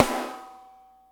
snare12.mp3